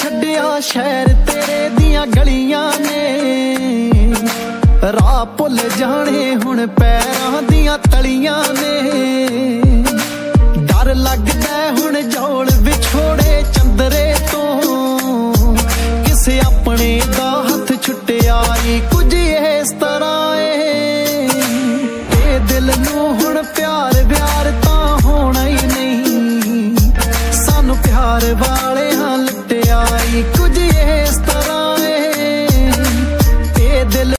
Punjabi Songs Ringtones
Romantic Ringtones